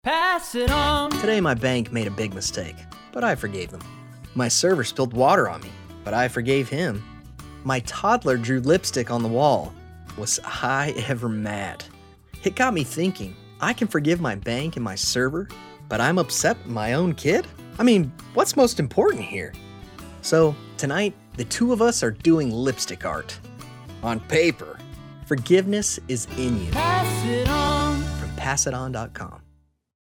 We’re excited to share our new PSA Radio spots that will inspire and motivate.